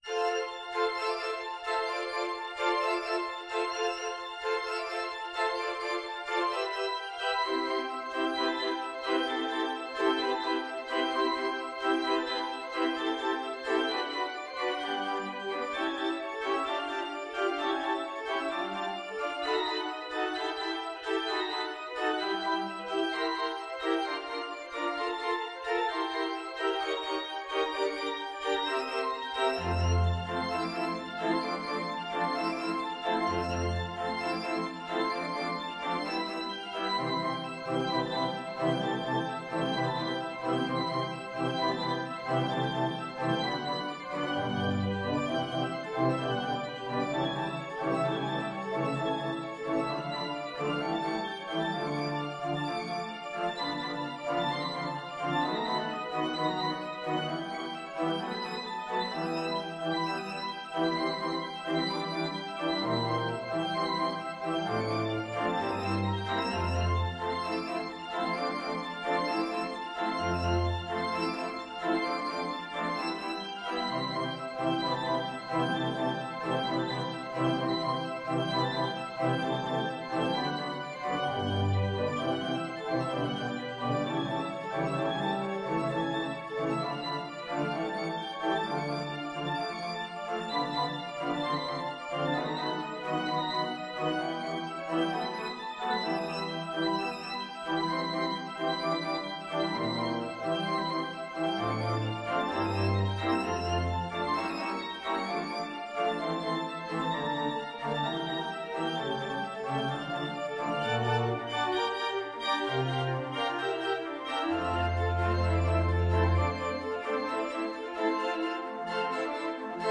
No parts available for this pieces as it is for solo piano.
Presto =130 (View more music marked Presto)
4/2 (View more 4/2 Music)
Piano  (View more Advanced Piano Music)
Classical (View more Classical Piano Music)